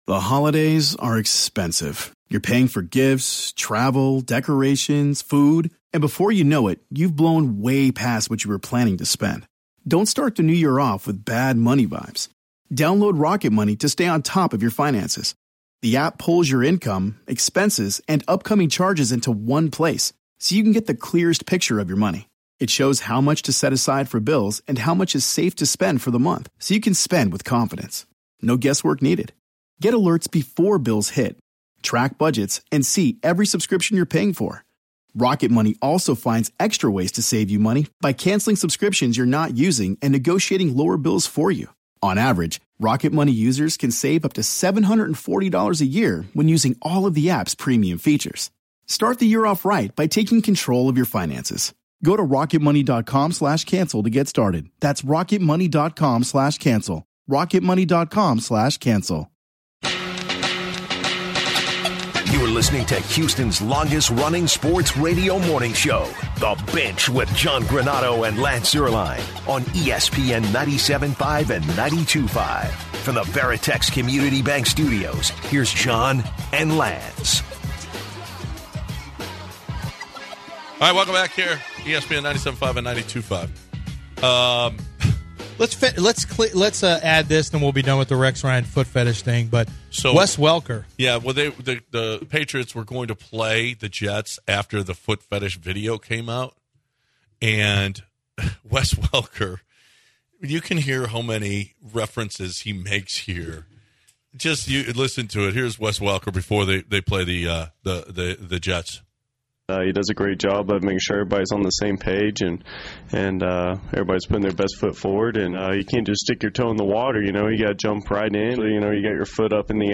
In the final hour of the show the guys bring things back to the Rockets win over Atlanta last night to remain relatively hot then Sage Rosenfels calls into the show to talk some Texans and Davis Mills.